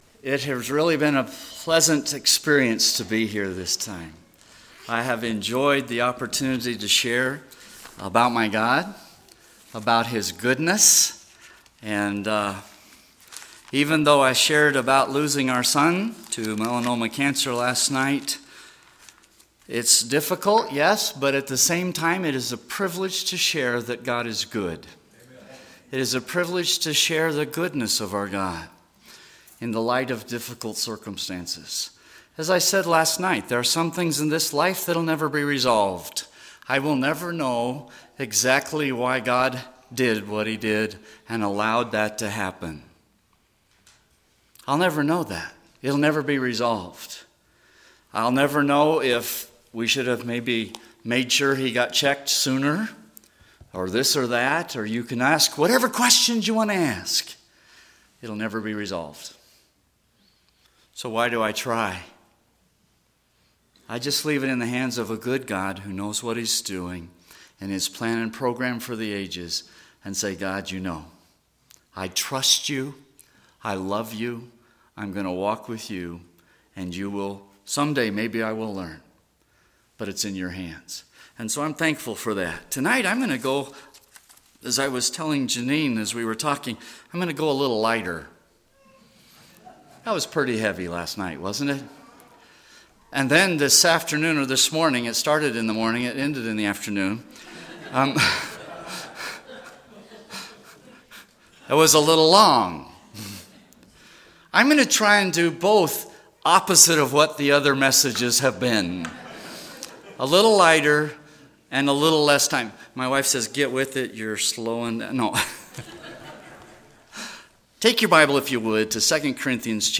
Northwest Baptist Missions Annual Meeting…
Wednesday Evening Service